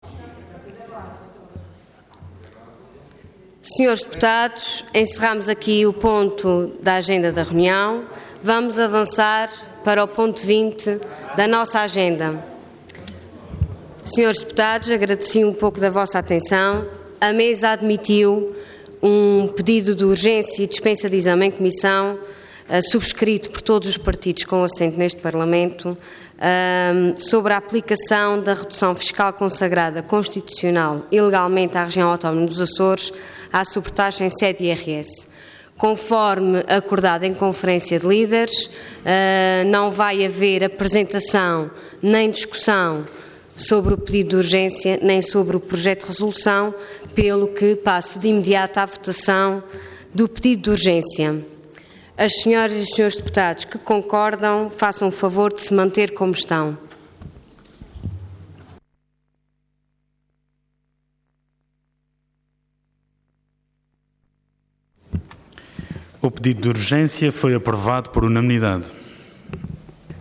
Intervenção
Presidente da Assembleia Regional